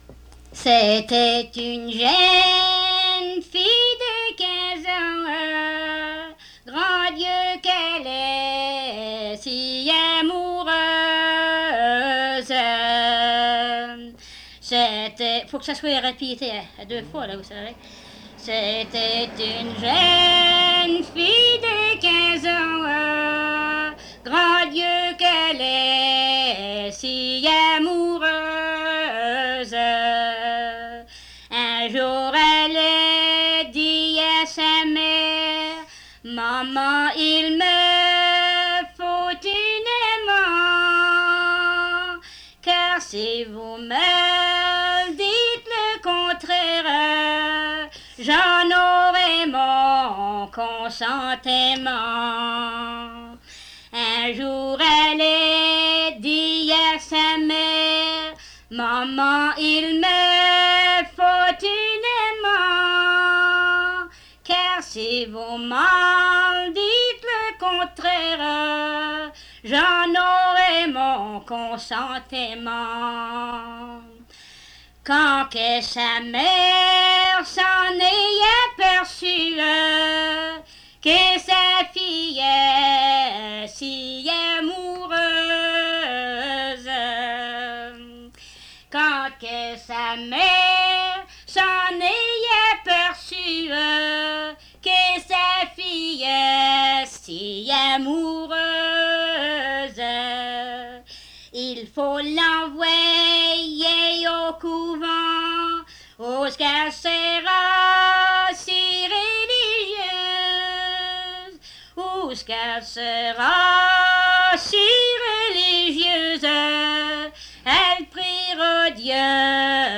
Chanson
Emplacement Cap St-Georges